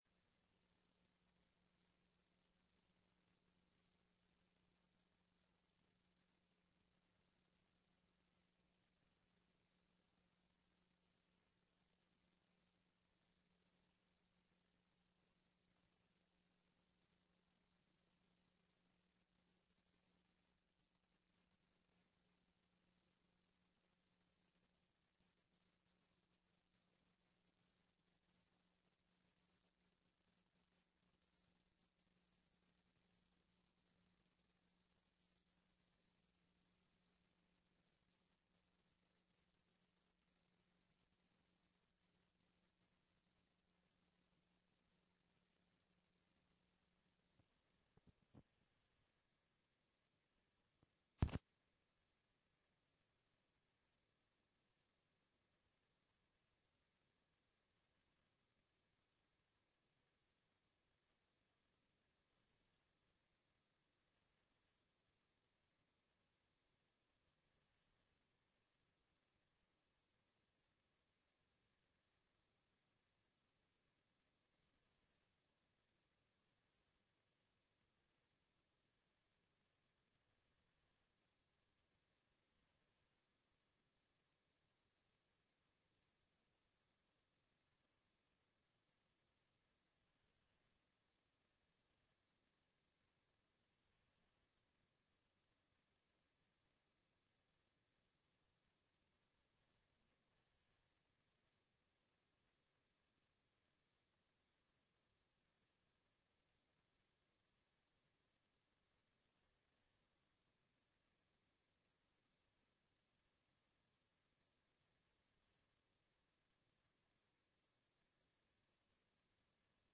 Sessão plenária do dia 23/11/15